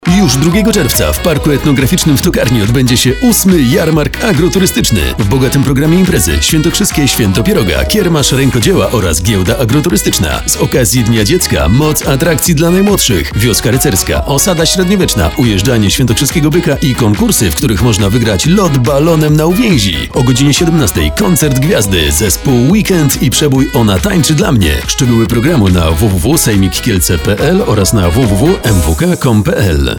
zapowiedź
radio_kielceweekend_spot.mp3